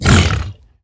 latest / assets / minecraft / sounds / mob / hoglin / hurt1.ogg
hurt1.ogg